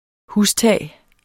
Udtale [ ˈhusˌtæˀj ]